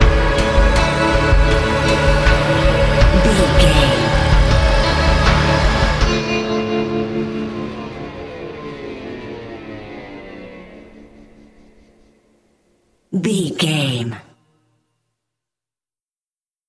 Thriller
Aeolian/Minor
Slow
drum machine
synthesiser
electric piano
electric guitar
tension
ominous
dark
suspense
haunting
creepy